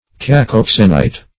Search Result for " cacoxenite" : The Collaborative International Dictionary of English v.0.48: Cacoxene \Ca*cox"ene\, Cacoxenite \Ca*cox"e*nite\, n. [Gr. kako`s bad + ????? guest.]